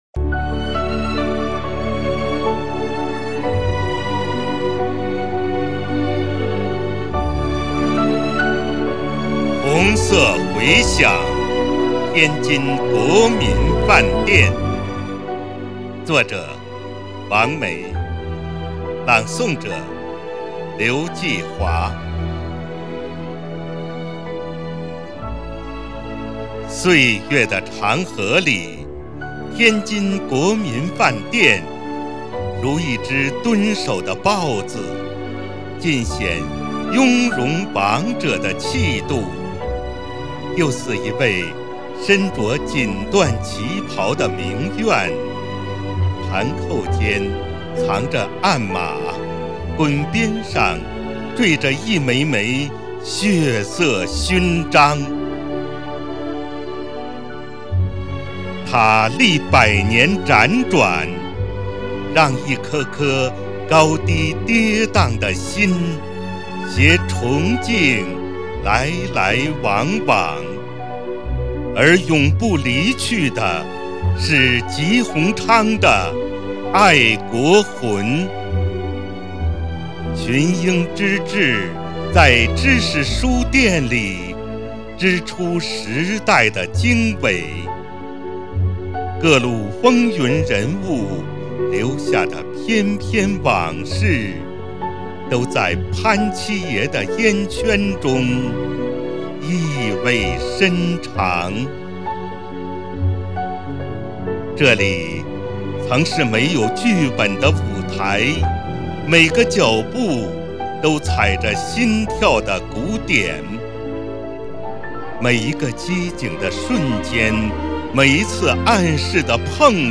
第十届全民创意写作“海河诗人”诗歌创作活动——成人组（十七）